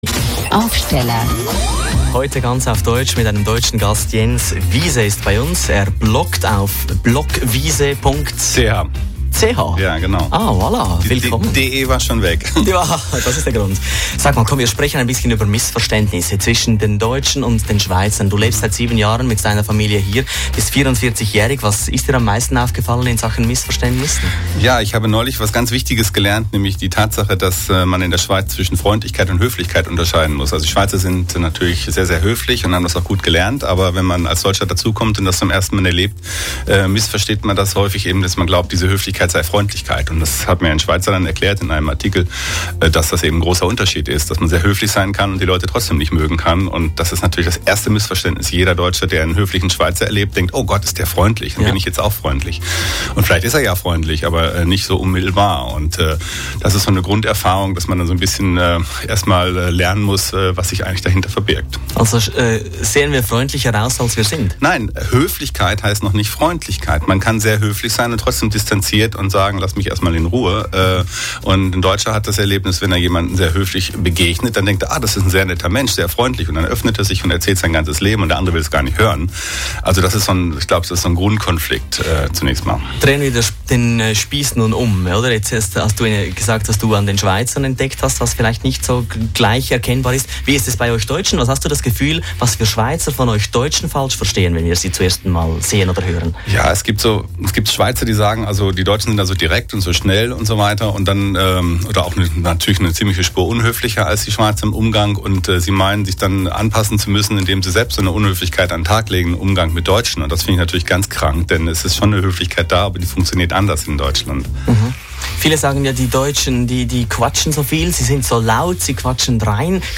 [Die drei Interview-Teile von heute früh sind nun als MP3 Files online und könnnen hier (Teil1), hier (Teil2) und
• Zu Gast im Radio